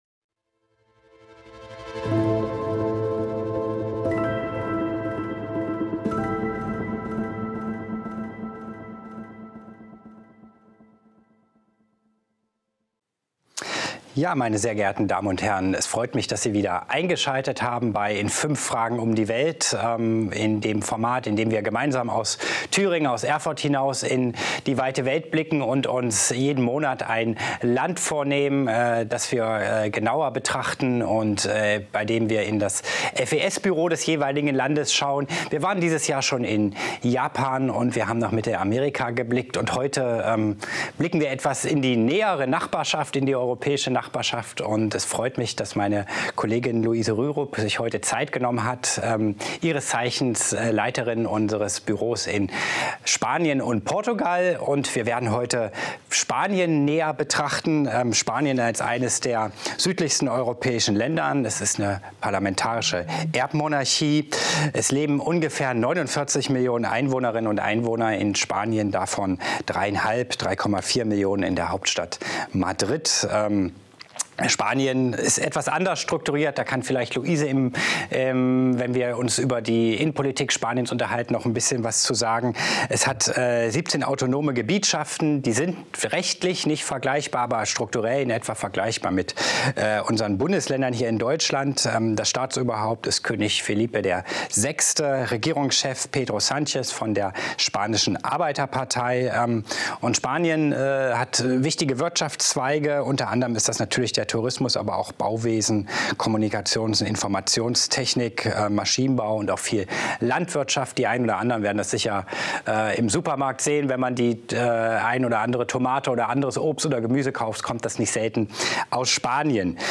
Zwei junge Redakteurinnen bei ihrer ersten Umfrage.